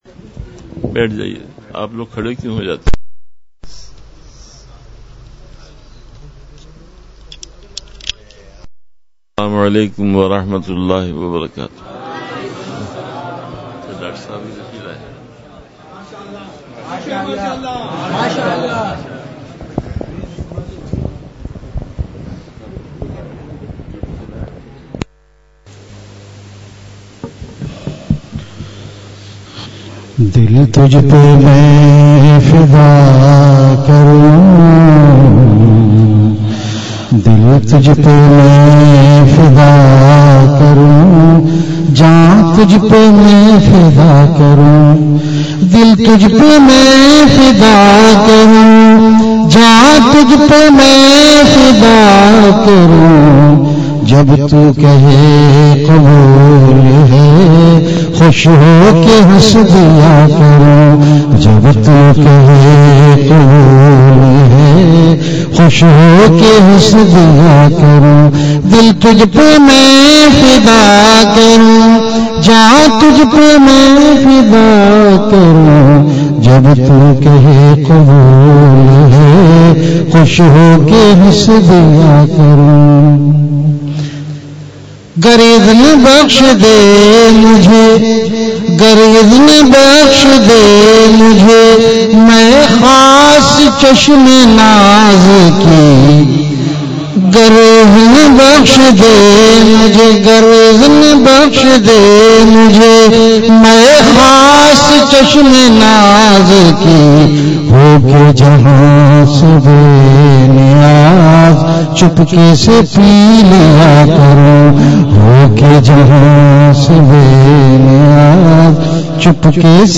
اصلاحی مجلس کی جھلکیاں(کلک کریں)